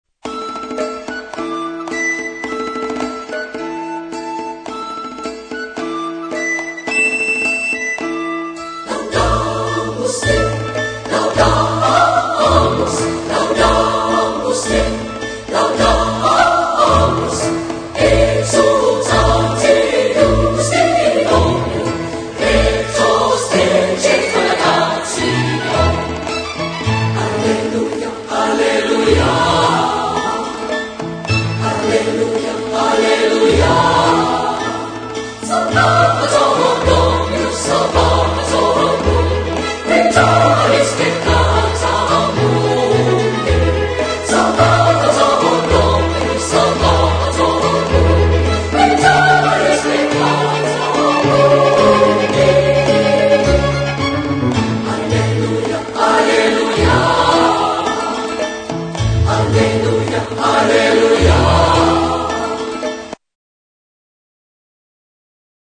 Instrumentos: Oboe (1) ; ; ; Tambor vasco (1)